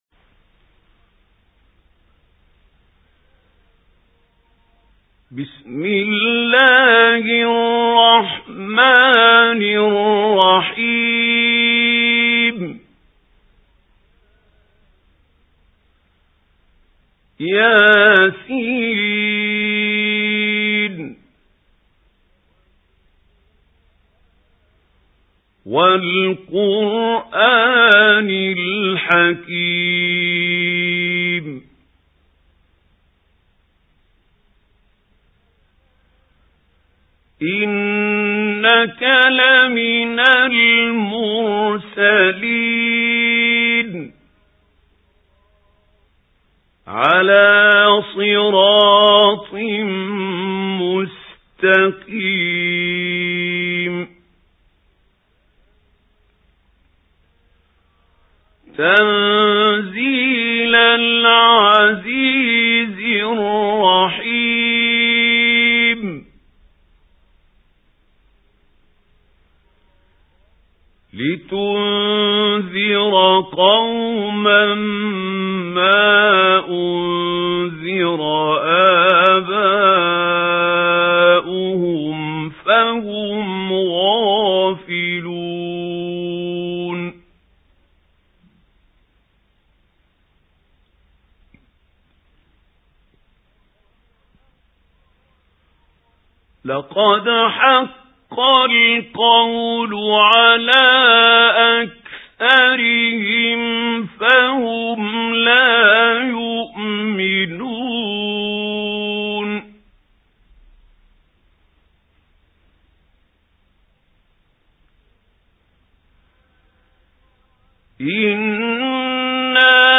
سُورَةُ يسٓ بصوت الشيخ محمود خليل الحصري